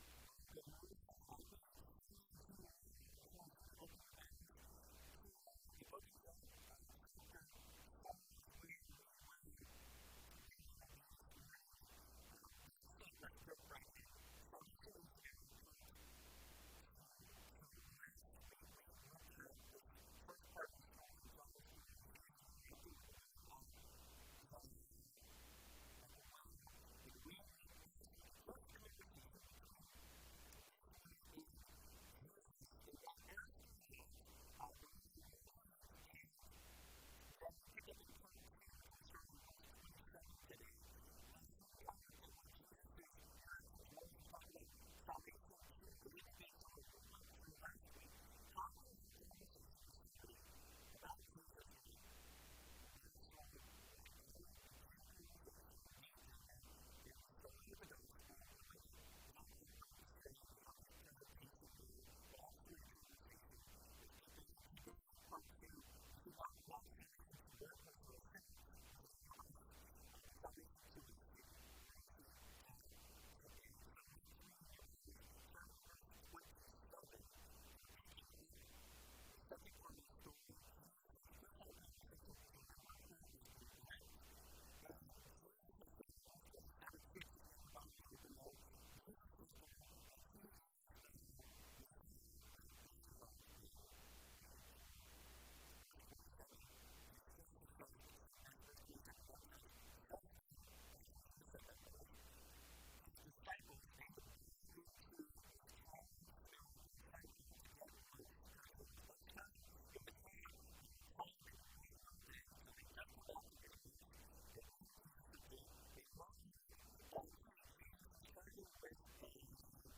This is part 2 of Jesus time in Samaria, covering His discussion with the desciples after His encounter with the woman at the well, continuing to model for us how to share the Gospel. Sermon Notes:Coming soon.